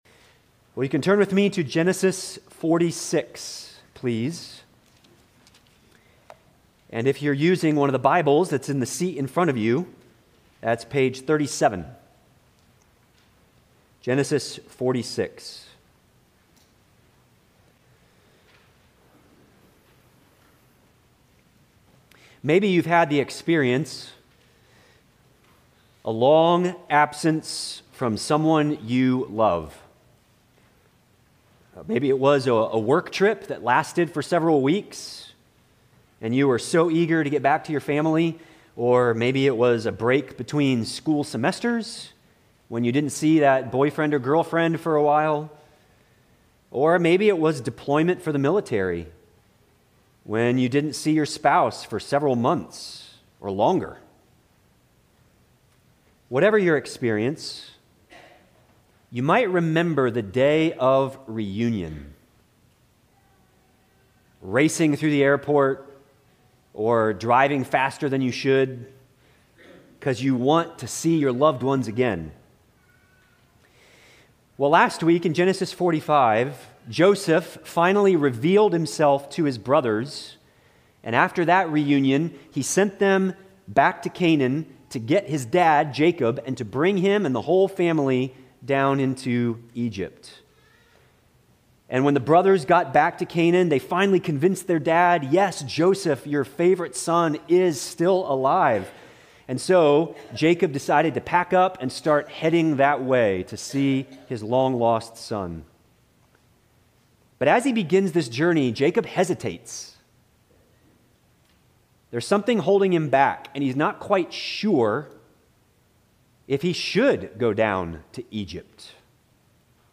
Sermons – Bethany Baptist Church Brevard, NC